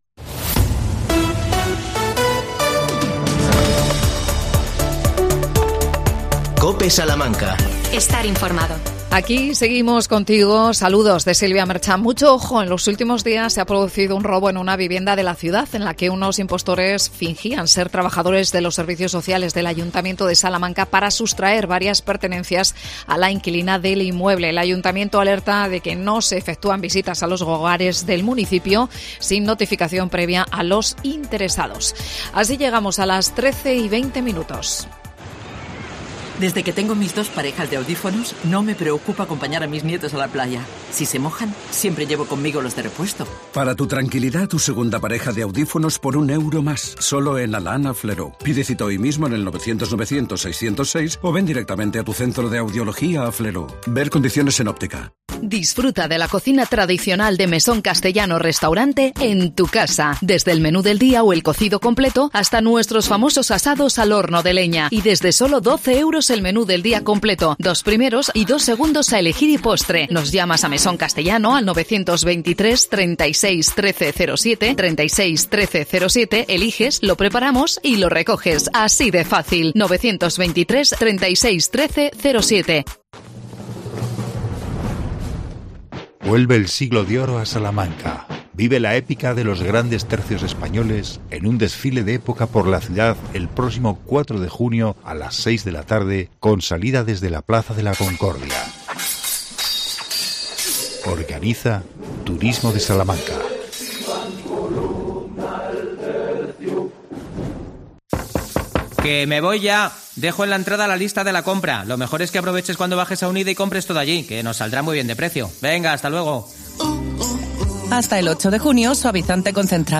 AUDIO: Romería de Majadas Viejas en La Alberca. Hablamos con su alcalde Miguel Ángel Luengo.